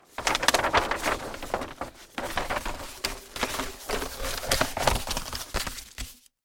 Звуки плаката
Звук сворачивания постера в трубку